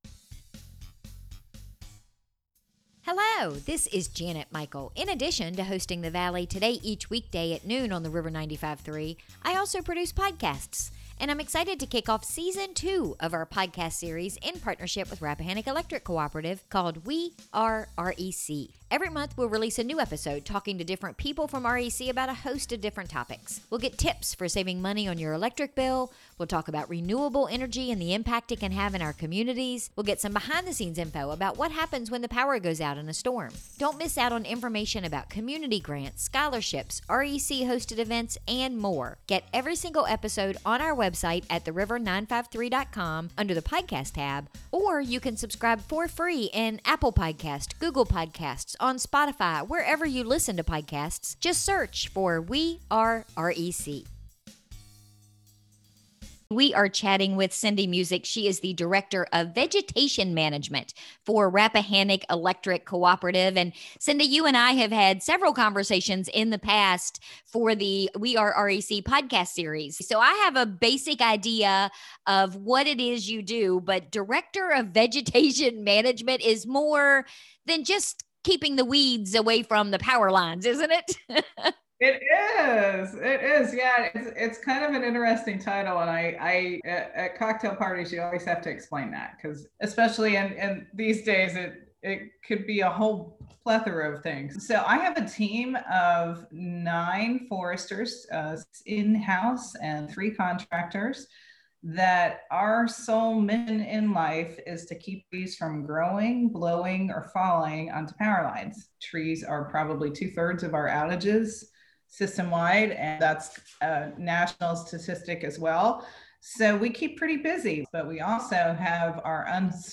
We recorded today’s episode via Zoom